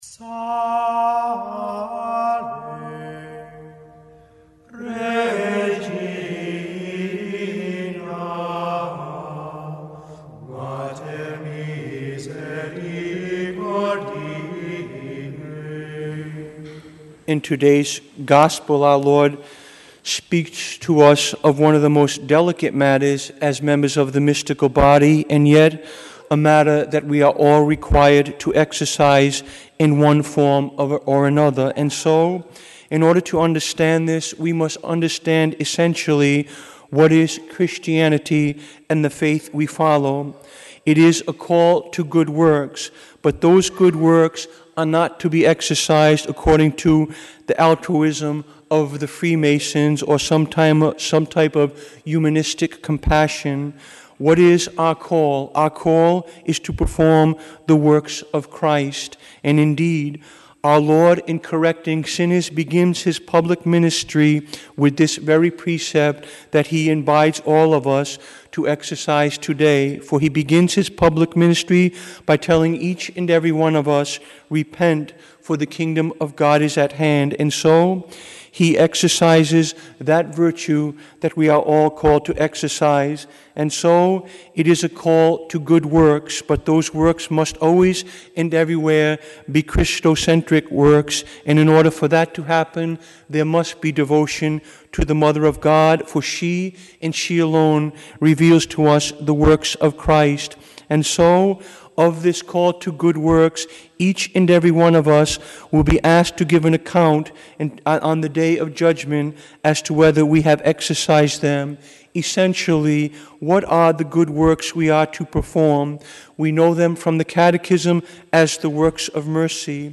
Homily
Mass: 23rd Sunday in Ordinary Time - Sunday - Form: OF Readings: 1st: eze 33:7-9 Resp: psa 95:1-2, 6-7, 8-9 2nd: rom 13:8-10 Gsp: mat 18:15-20 Audio (MP3) +++